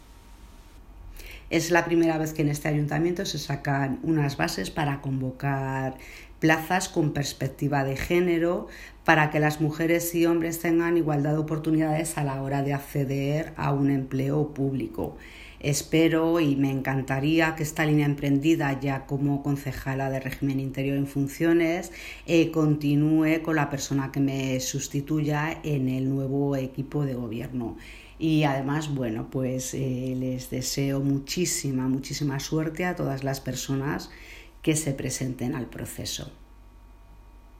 Audio - Susana García (Concejala de Régimen interior) Sobre plazas bombero perspectiva genero